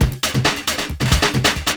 14 LOOP10 -R.wav